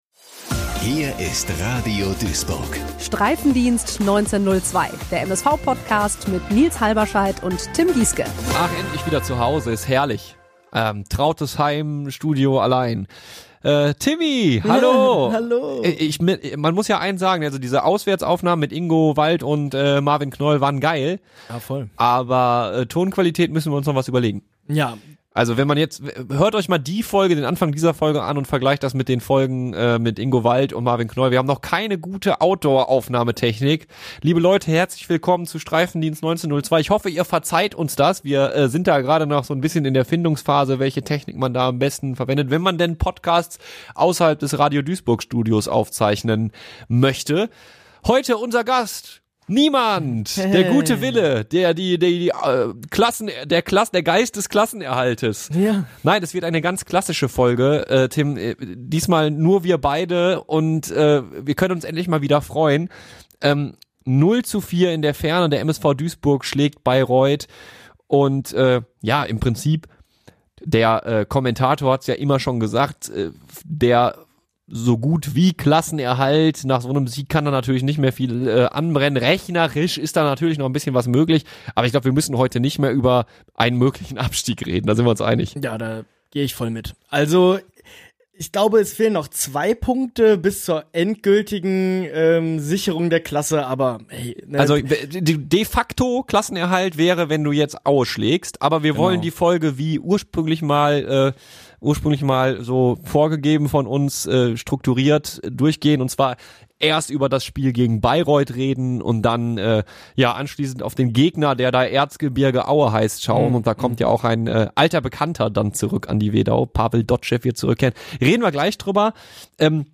Nachdem die letzten beiden Folgen auswärts aufgenommen wurden, wurde sich für diese Folge mal wieder gemütlich ins Studio gesetzt.